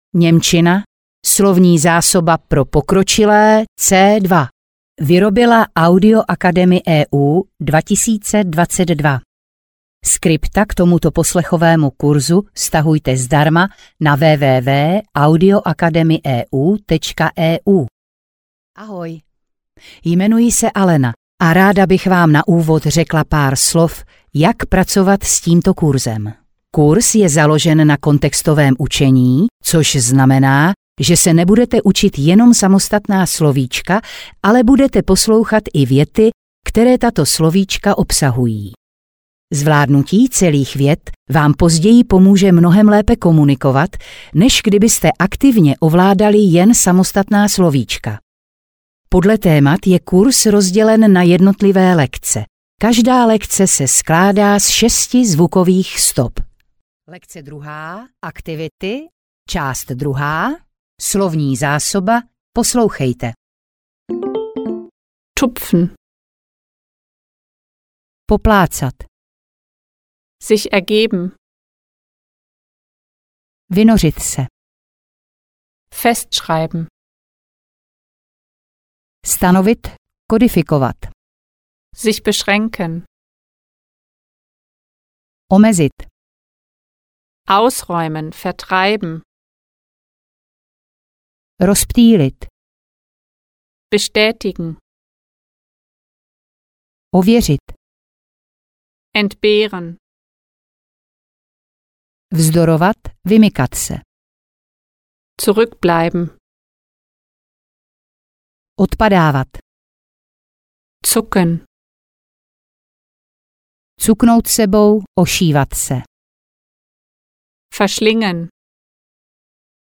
Němčina pro pokročilé C2 audiokniha
Ukázka z knihy